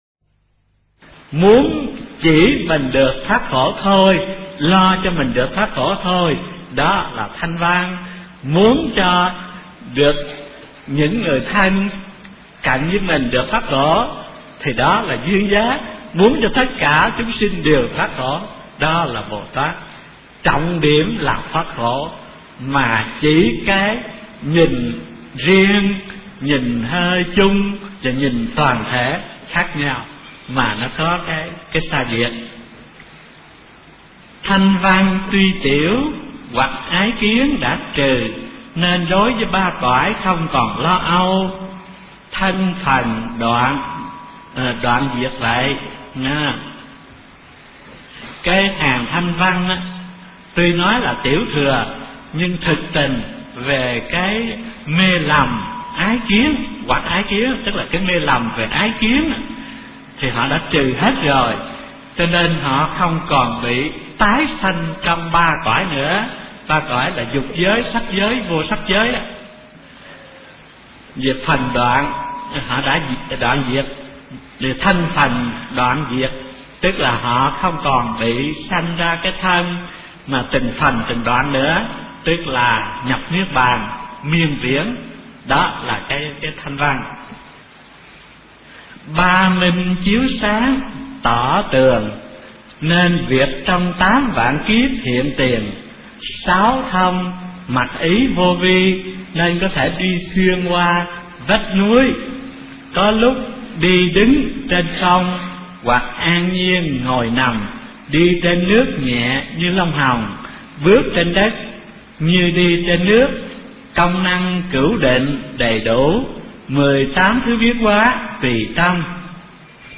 Kinh Giảng Thiền Tông Vĩnh Gia Tập - Thích Thanh Từ